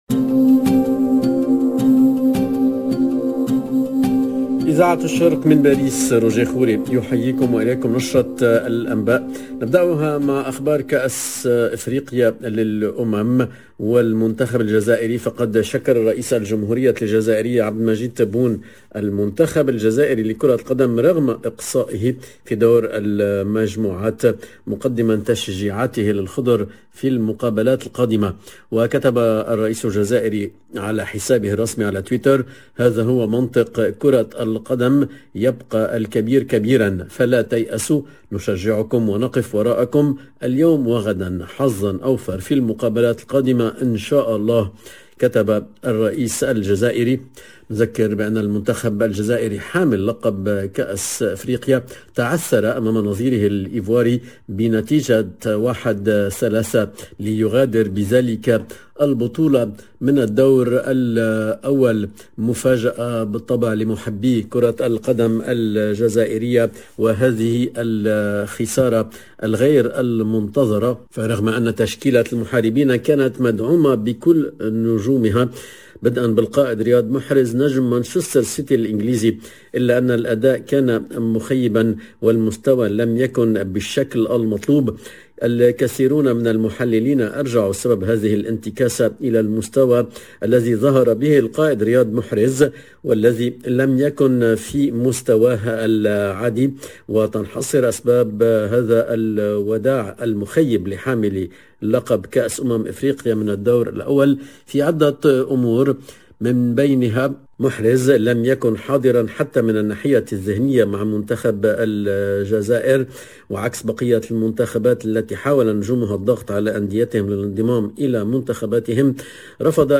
LE JOURNAL DE LA MI-JOURNEE EN LANGUE ARABE DU 21/01/22